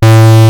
MINIMOOG.wav